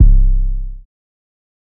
808 (Metro)_4.wav